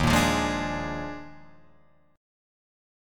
D#M7sus2sus4 chord